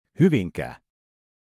Hyvinkää (Finnish: [ˈhyʋiŋkæː]
Fi-Hyvinkää.ogg.mp3